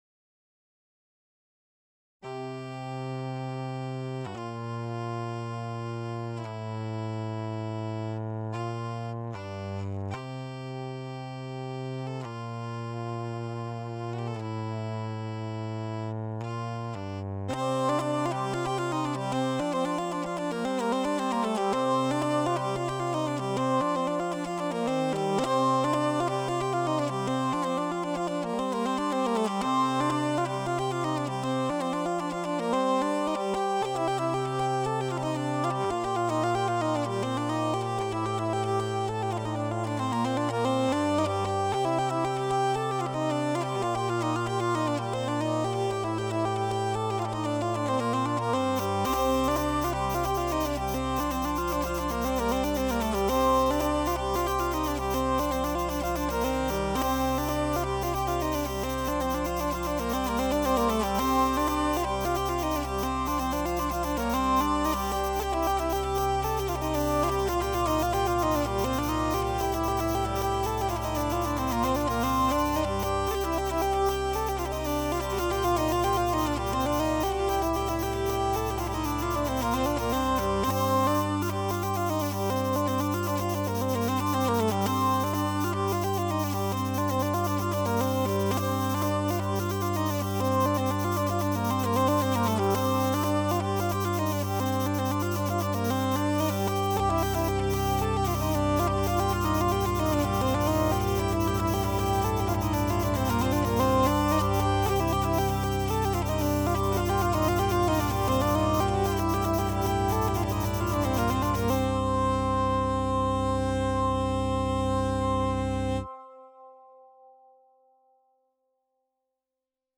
:mp3:2009:stages:vielle
branle.mp3